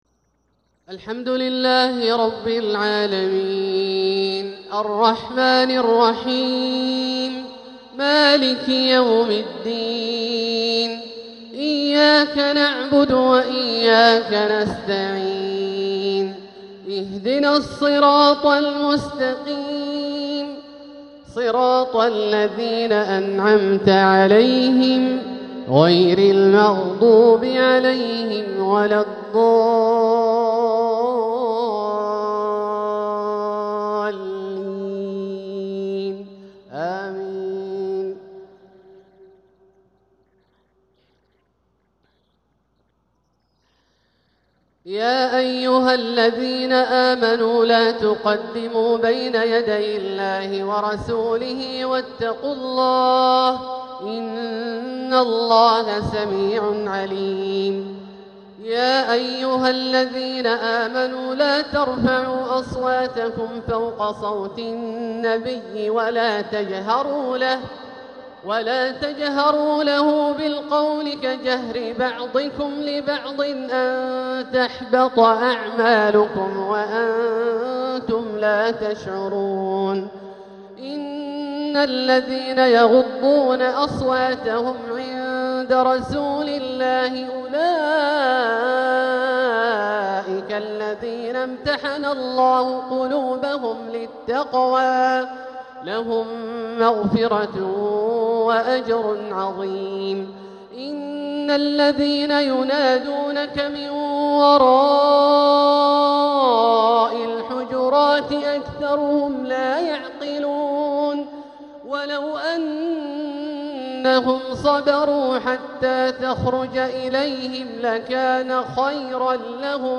تلاوة لفواتح سورة الحجرات | عشاء الخميس 13 صفر 1447هـ > ١٤٤٧هـ > الفروض - تلاوات عبدالله الجهني